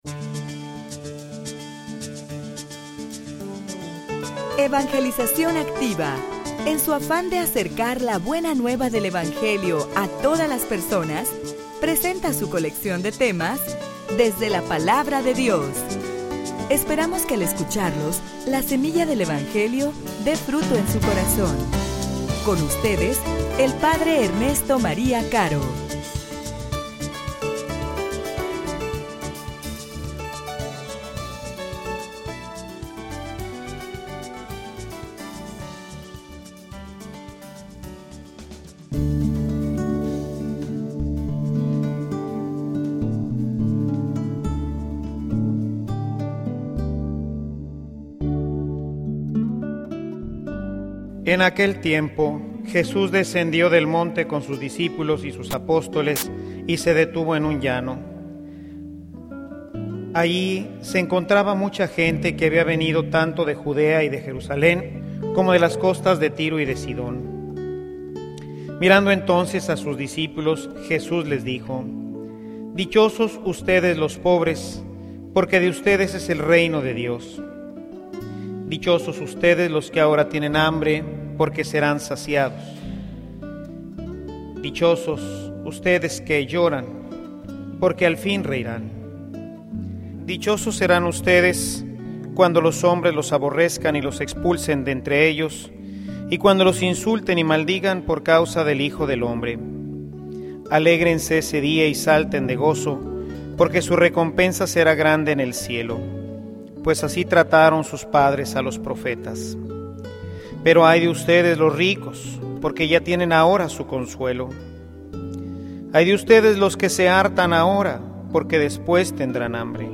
homilia_Una_decision_radical.mp3